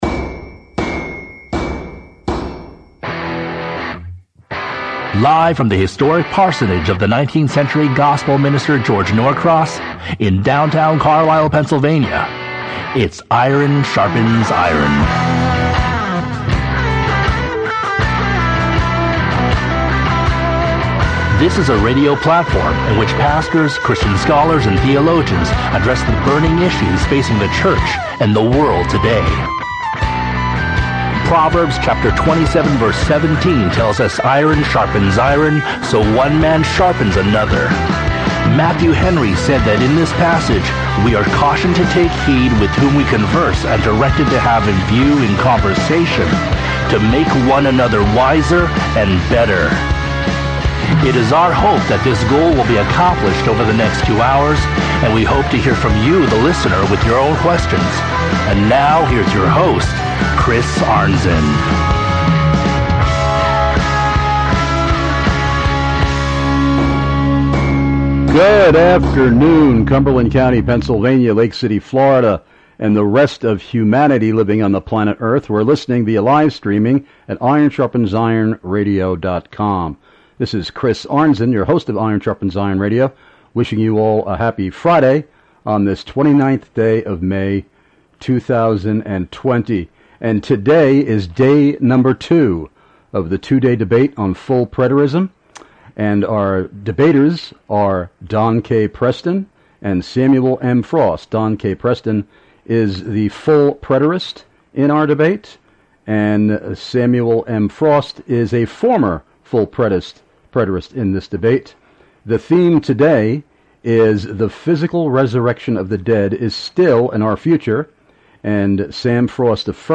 2-DAY DEBATE on “FULL PRETERISM”!!